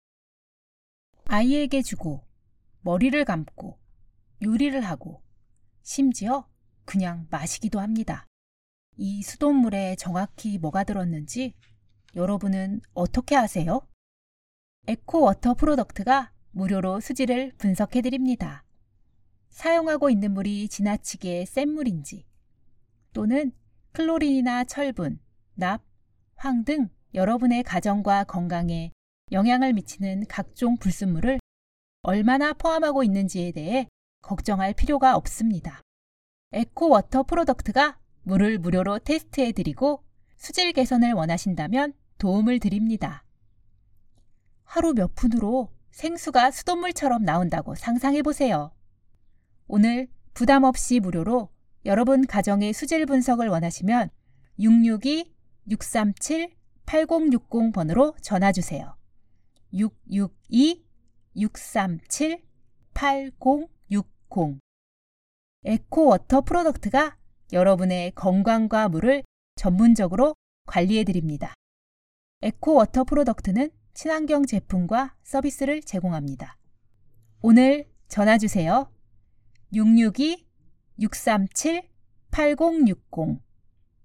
Voiceover Artists
EQHO provides multi-language solutions from its in-house recording facilities
Korean Female 03999
NARRATION
COMMERCIAL